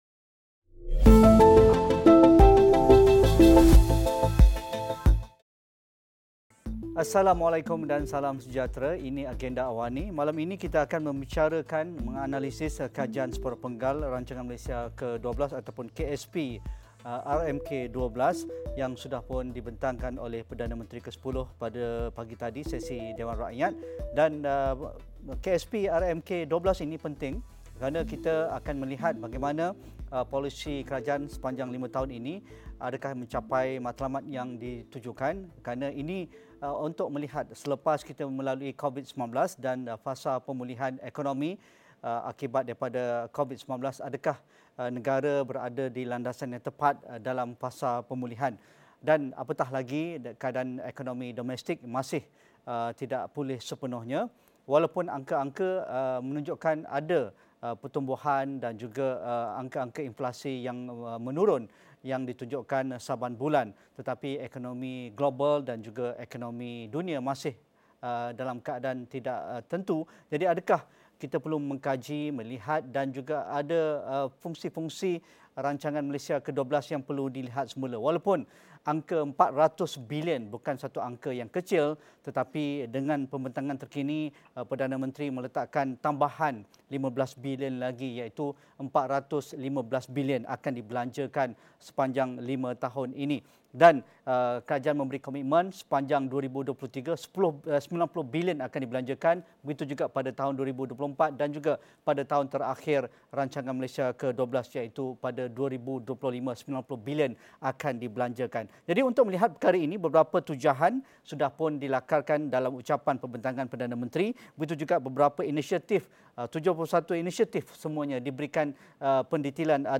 Bagaimana Kajian Separuh Penggal Rancangan Malaysia Ke-12 (KSP RMKe-12) mampu noktahkan isu kemiskinan, merancakkan semula ekonomi dan meningkatkan pendapatan? Diskusi 8.30 malam